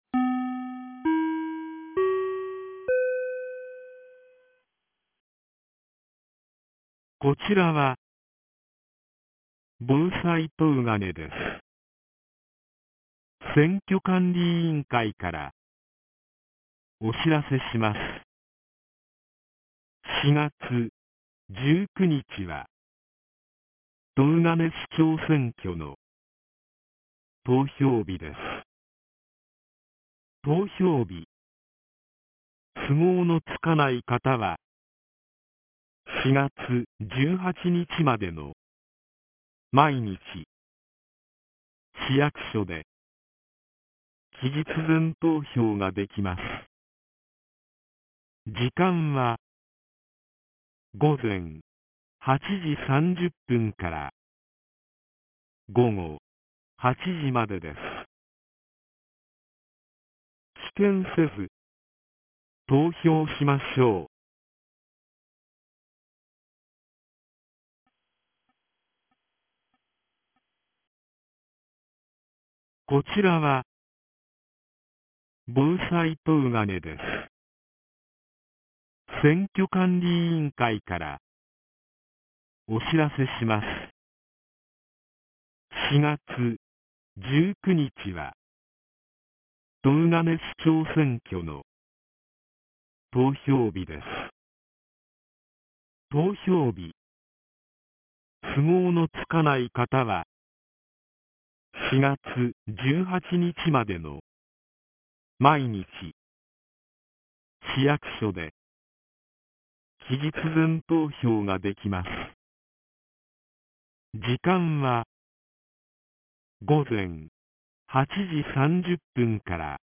2026年04月15日 15時48分に、東金市より防災行政無線の放送を行いました。